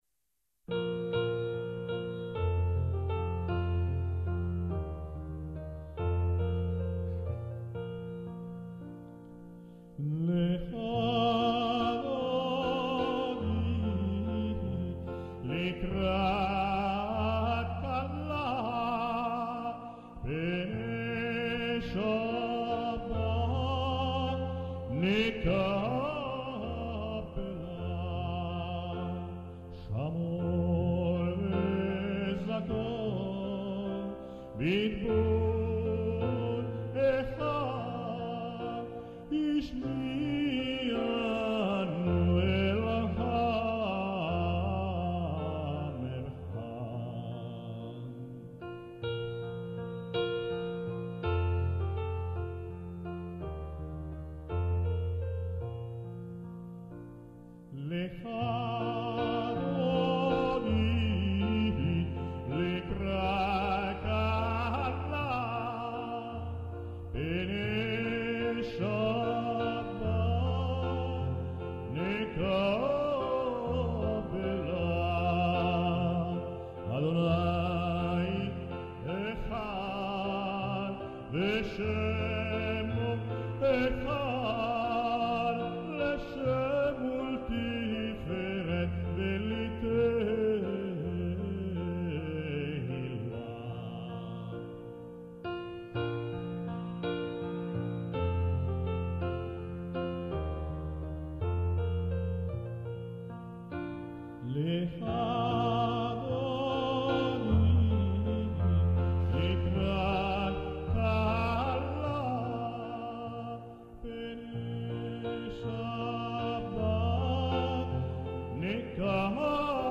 Lekhà dodì canto sinagogale in ebraico per l'avvento dello Sciabbà
piano
registrazione effettuata al Teatro Paisiello di Lecce il 27 gennaio 2004